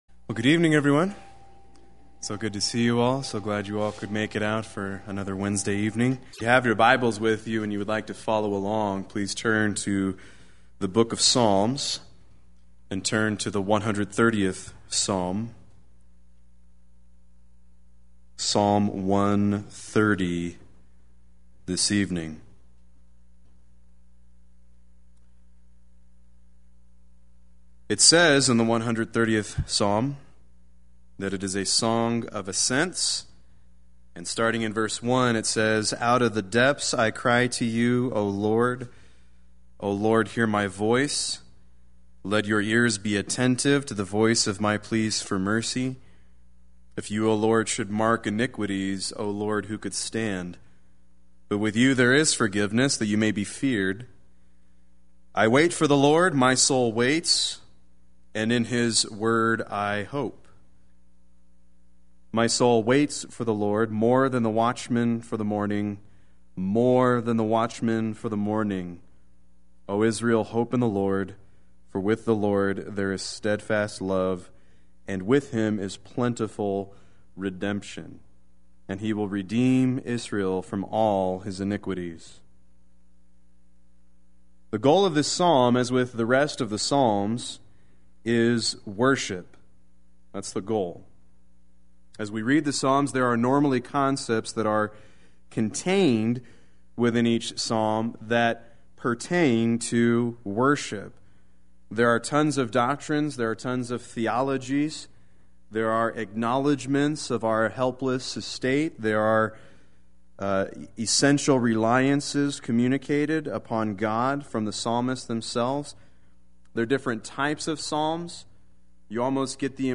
Play Sermon Get HCF Teaching Automatically.
Right Attitudes of Worship Lead to Right Acts of Worship Wednesday Worship